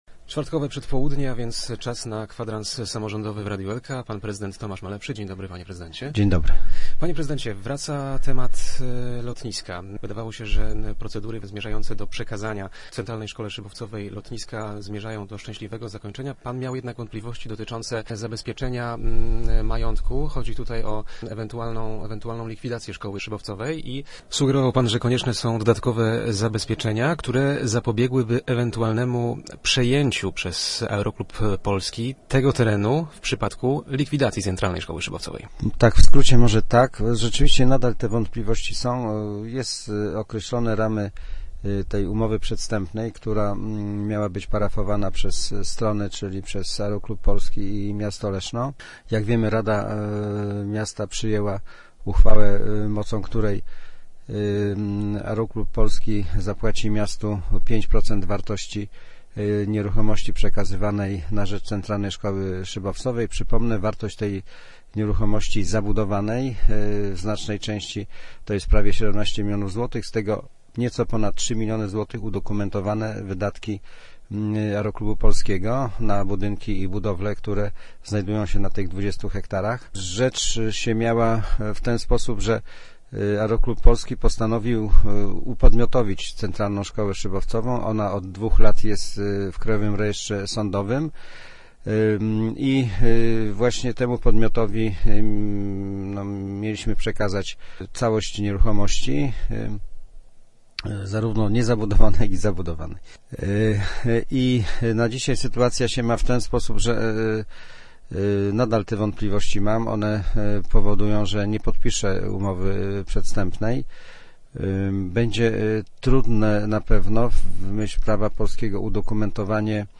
Gościem Kwadransa był prezydent Leszna, Tomasz Malepszy ...